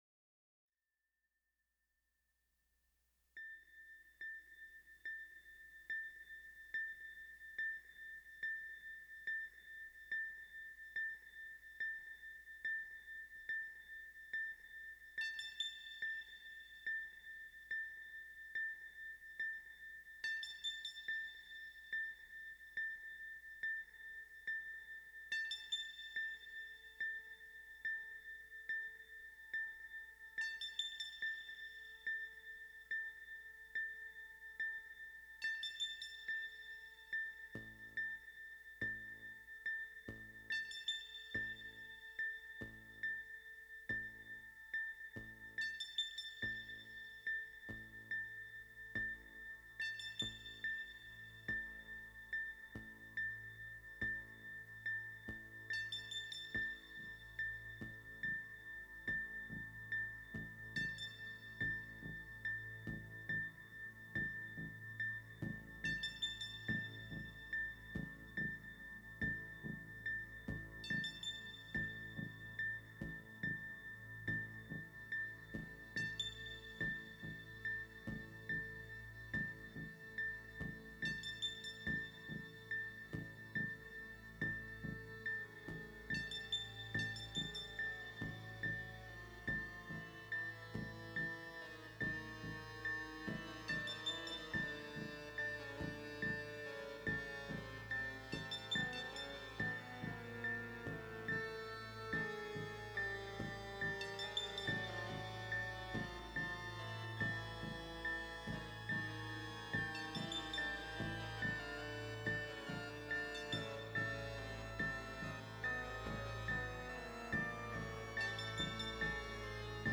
Genre: Electronic, Stage & Screen
Style: Soundtrack, Modern Classical, Score, Experimental